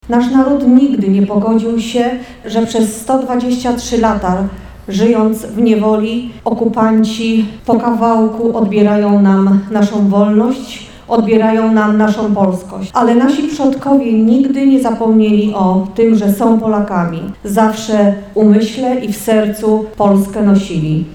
Powstanie styczniowe to jeden ze zrywów niepodległościowych, których Polaków poniósł do walki o wolną i niepodległą Polskę powiedziała obecna na uroczystości wojewoda świętokrzyski Agata Wojtyszek: